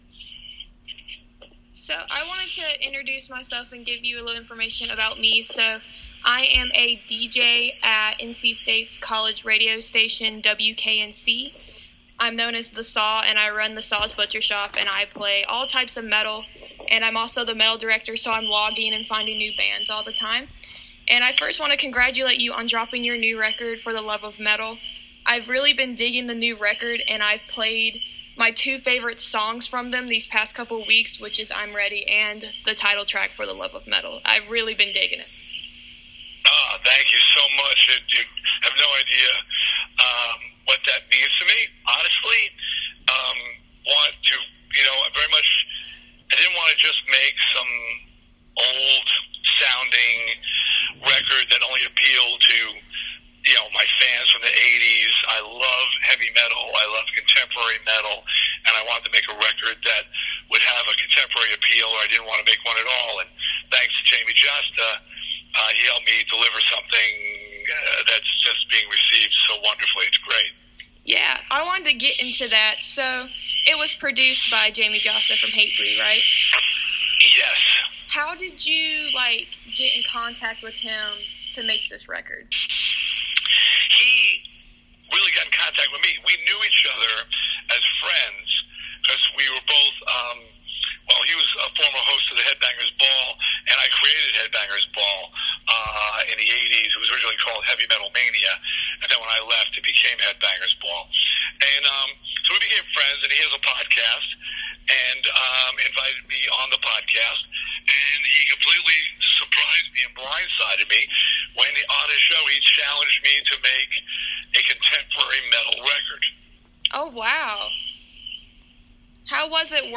Dee Snider Interview
This past Wednesday, I had the honor of having a phone call interview with one of the Metal Gods: Dee Snider.